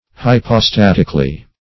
Hypostatically \Hy`po*stat"ic*al*ly\